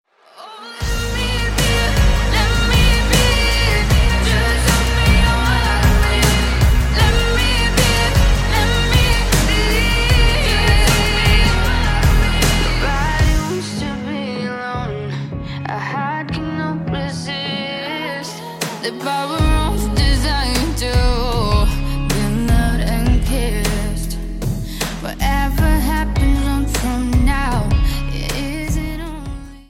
• Качество: 128, Stereo
поп
dance
Electronic
красивый женский вокал